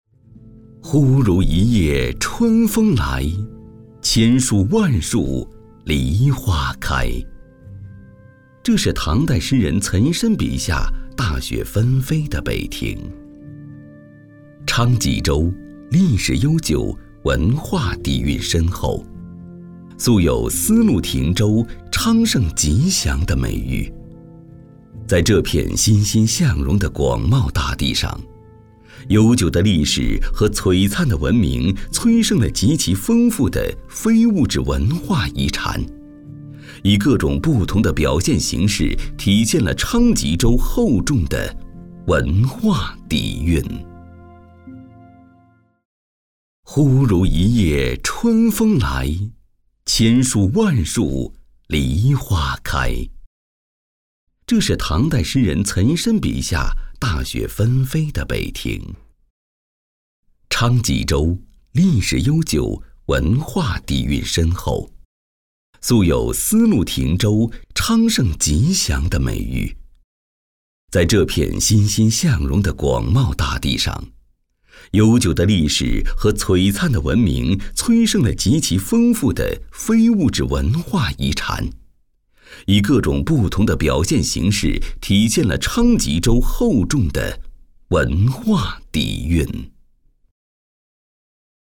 男69
长治宣传片